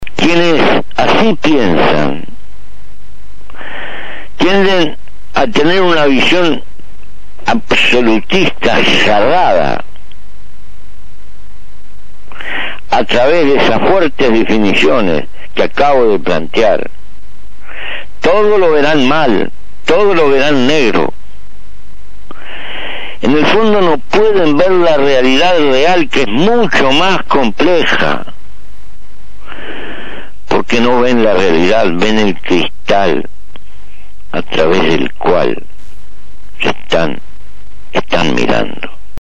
Audición radial